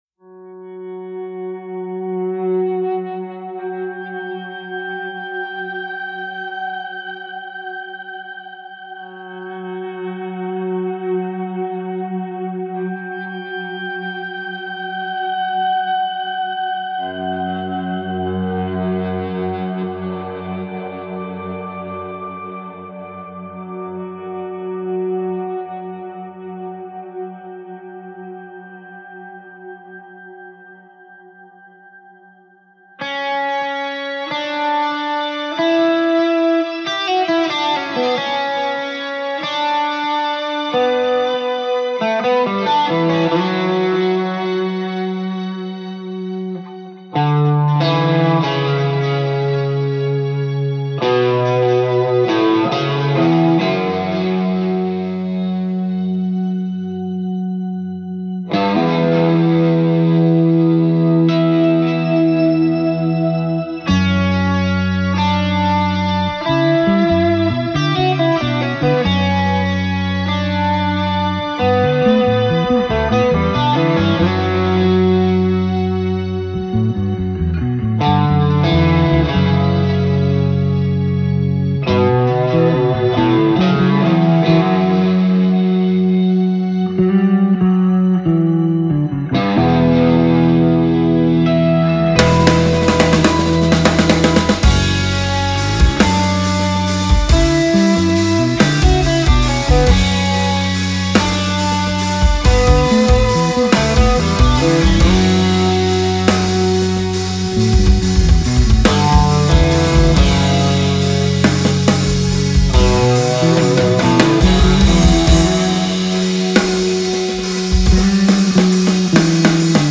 Genere: Fusion.